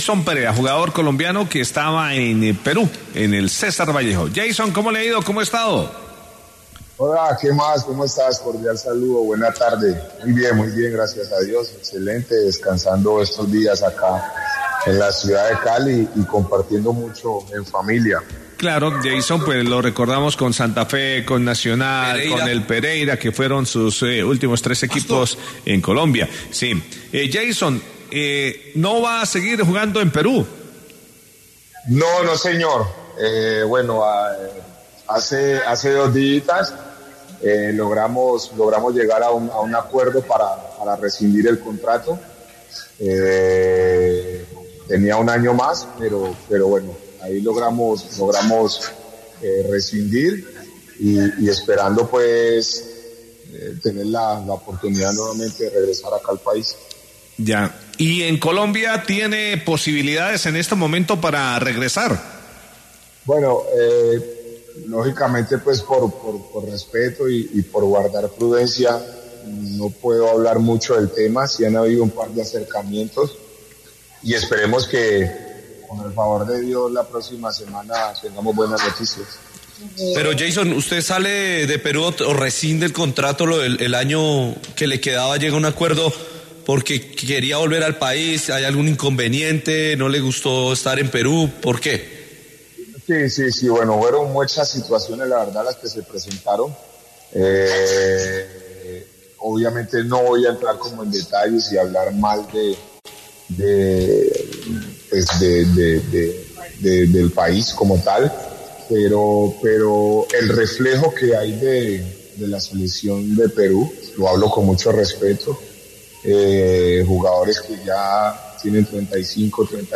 En exclusiva con Caracol Radio, el defensa central Geisson Perea dio a conocer algunos de los motivos por el cual dejó al club peruano y dio a entender que en los próximos días podría concretarse su fichaje por algún equipo colombiano.